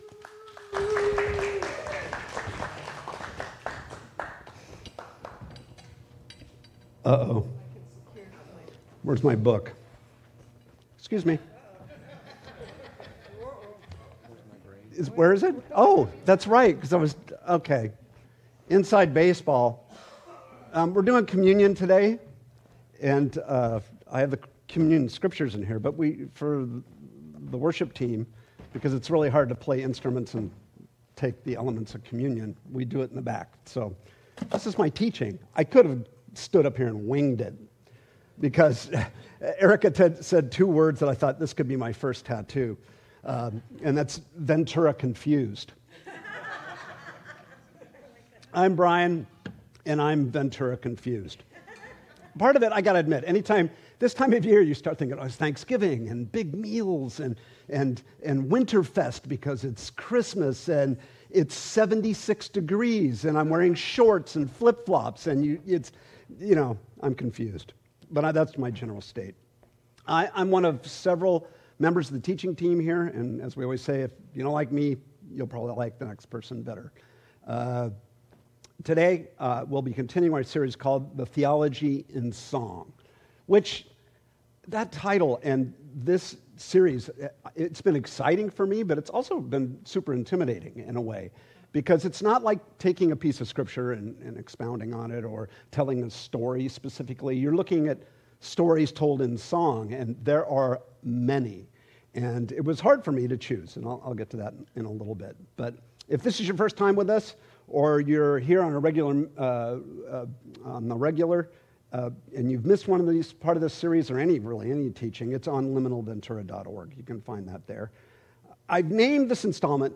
In this raw and soul-stirring conversation